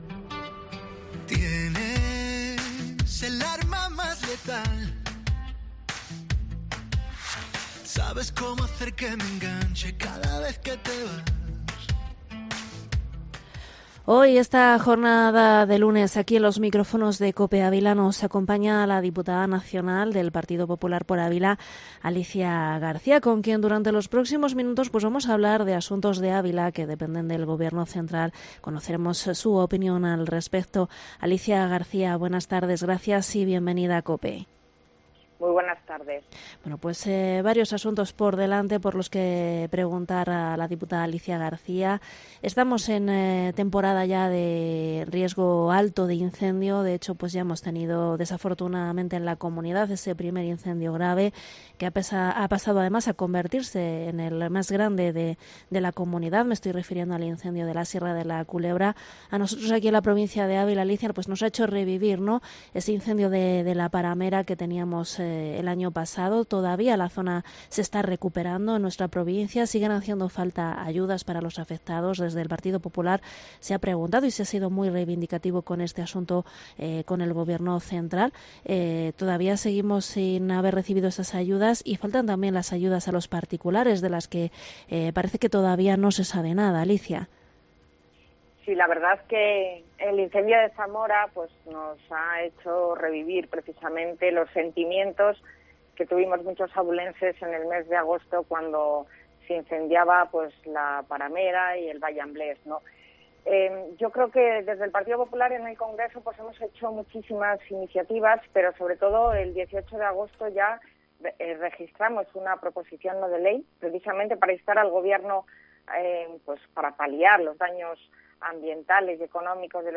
Entrevista a la diputada nacional, Alicia García en Cope Ávila